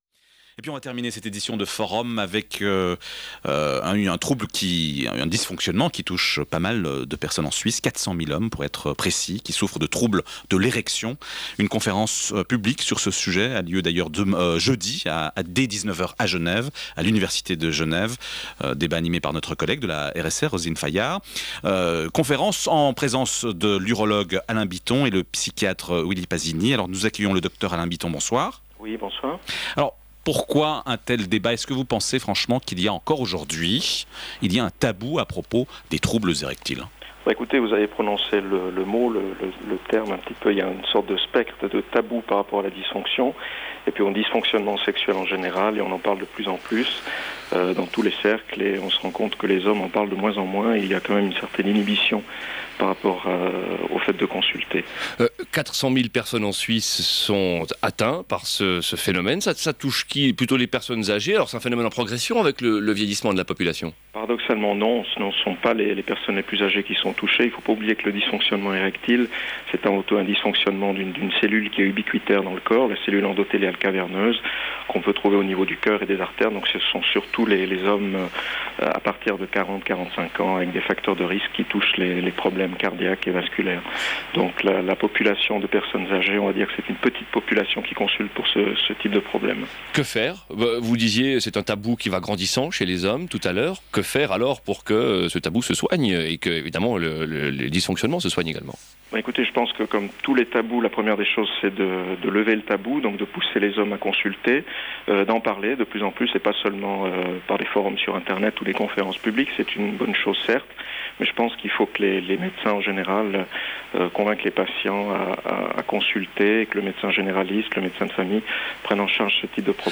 interview_rsr_14nov06.aif